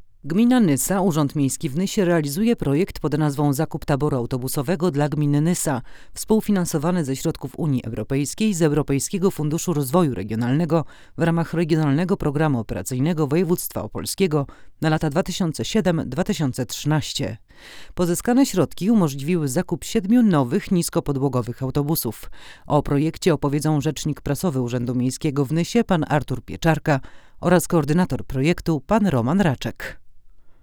Ustawiamy klasyczną nerkę i jedziemy.
Nagranie wokal żeński
Brzmienie jest pełne, ale z odrobiną piasku. Klarowne, ale nie sterylne.